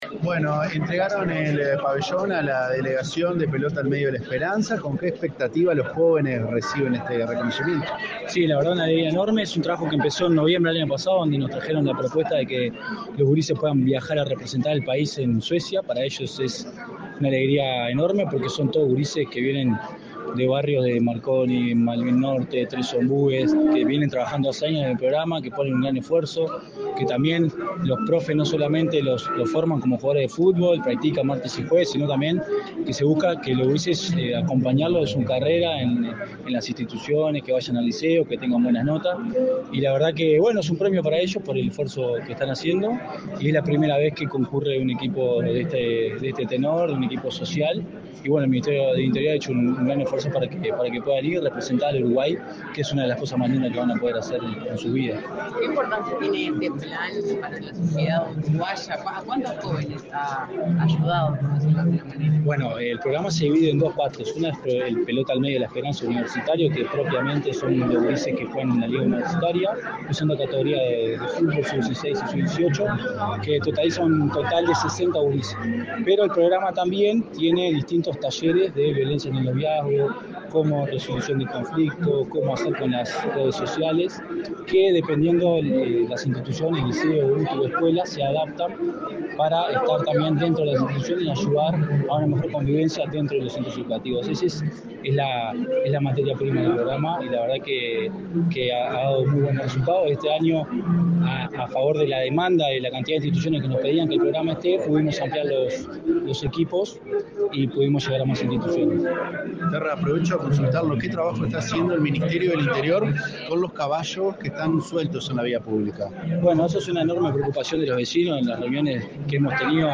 Declaraciones a la prensa del director de Convivencia y Seguridad Ciudadana del Ministerio del Interior, Matías Terra
Declaraciones a la prensa del director de Convivencia y Seguridad Ciudadana del Ministerio del Interior, Matías Terra 04/07/2024 Compartir Facebook X Copiar enlace WhatsApp LinkedIn Tras participar en la entrega del pabellón al grupo de competidores que participarán en la competencia Gothia Cup, en Suecia, este 4 de julio, el director de Convivencia y Seguridad Ciudadana, Matías Terra, realizó declaraciones a la prensa.
Terra prensa .mp3